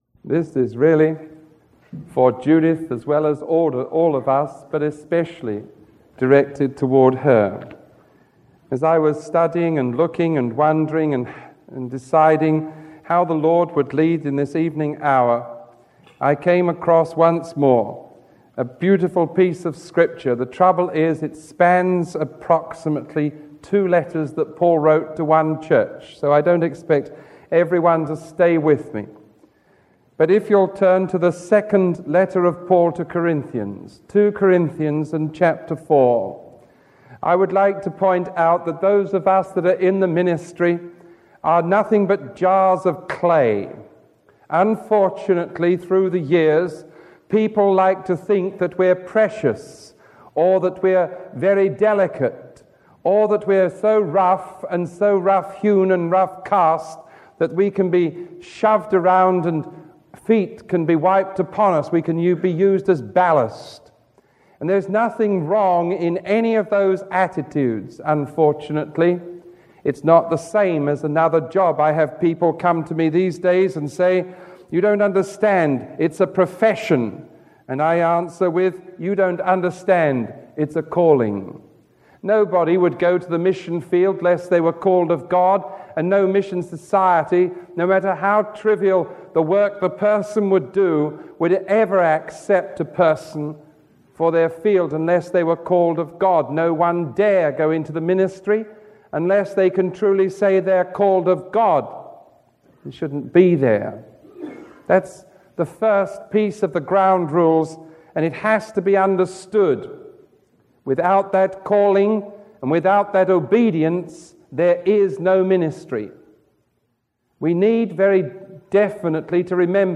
Sermon 1105A recorded on July 27